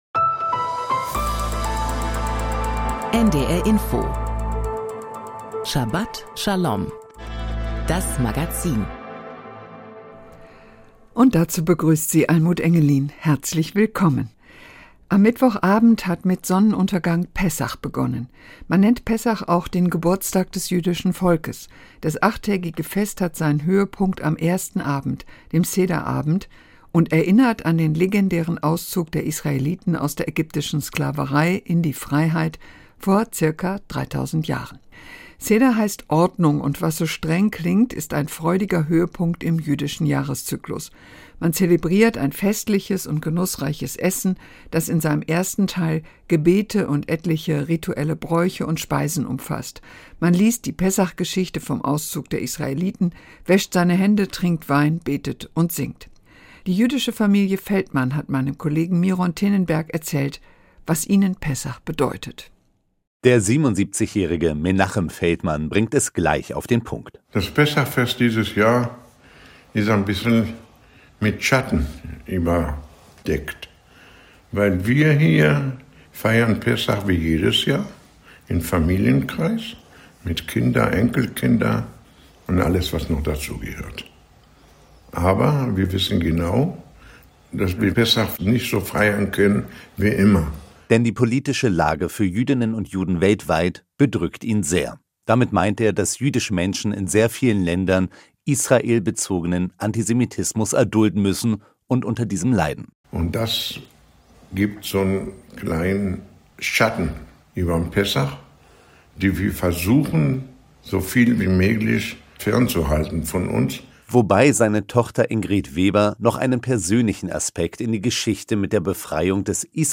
Interview
Thora-Auslegung